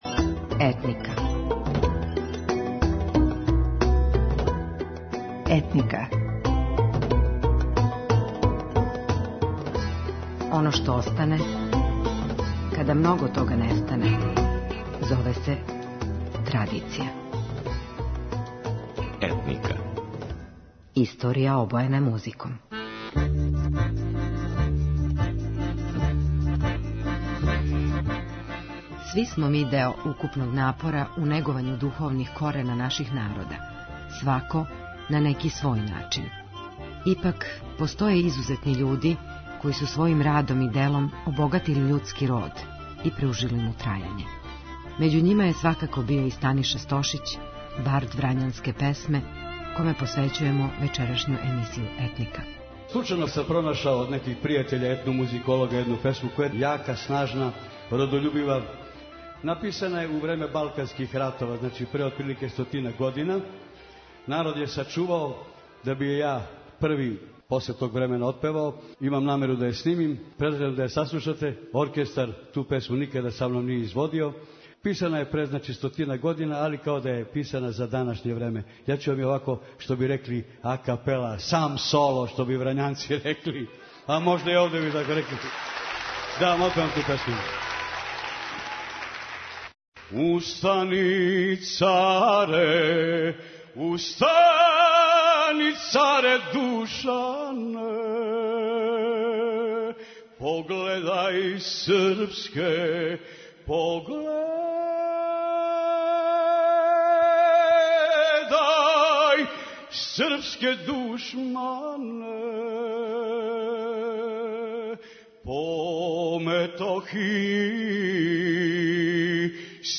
Гласом је заводио све који су га слушали. Био је непоновљив у својим емотивним интерпретацијама, посебно врањских песама.